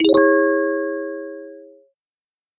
Этот звук ассоциируется с окончанием работы и поможет пользователям приложения чувствовать больше удовлетворения от проделанной задачи.